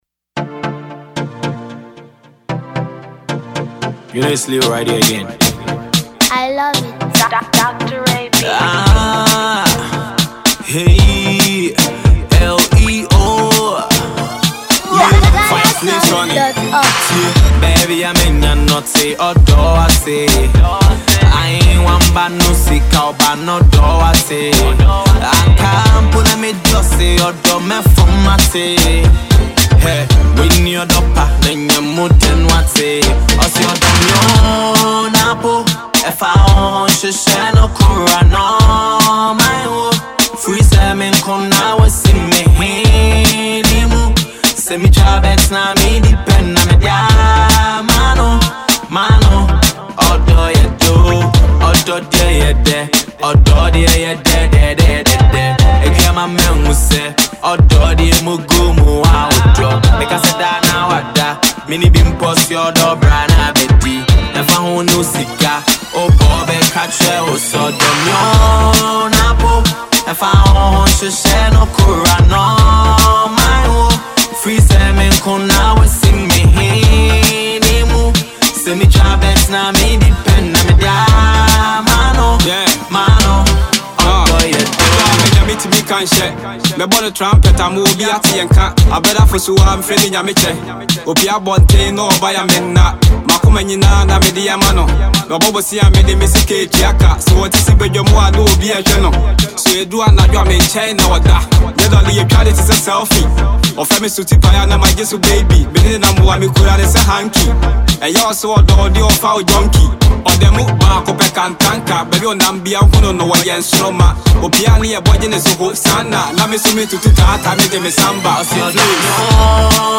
love tune